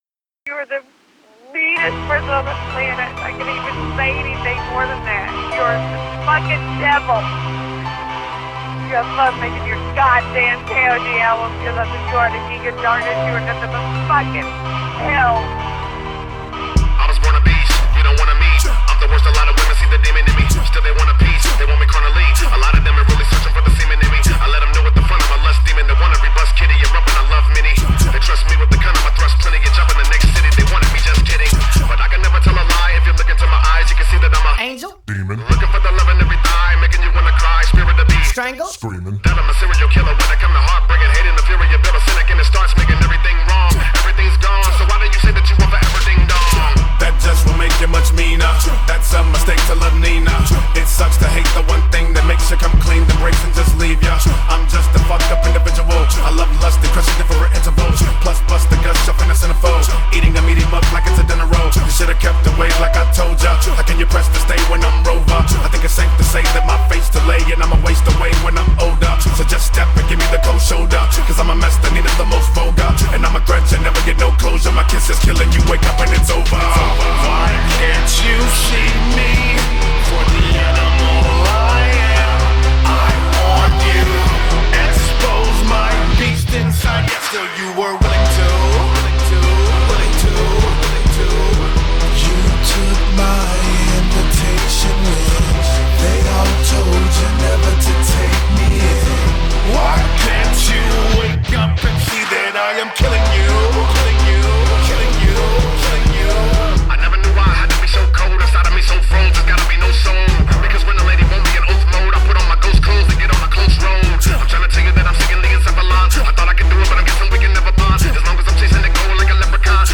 Audio QualityPerfect (High Quality)
Actual BPM is 125.